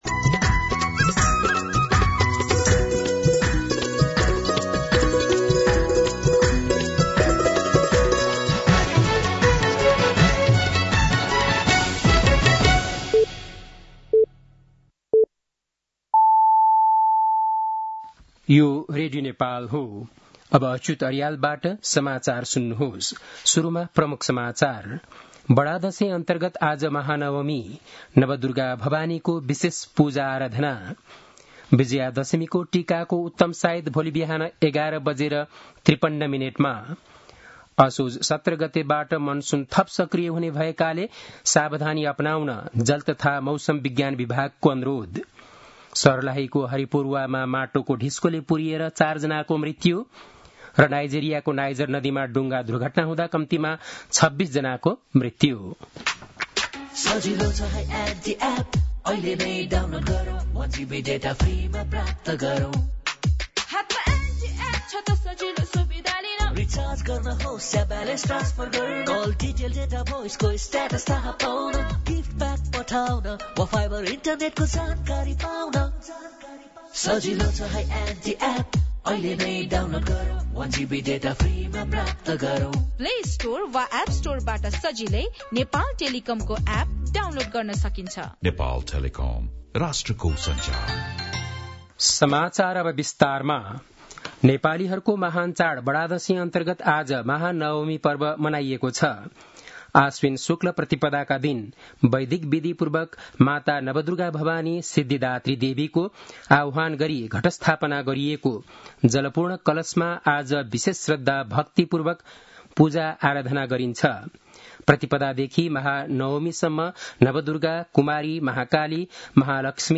बेलुकी ७ बजेको नेपाली समाचार : १५ असोज , २०८२
7-pm-news-6-15.mp3